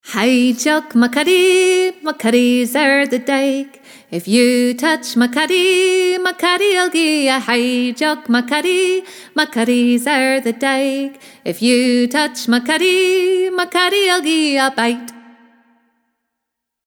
Scottish Music Download Hey Jock Ma Cuddie MP3